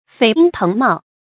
蜚英腾茂 fēi yīng téng mào
蜚英腾茂发音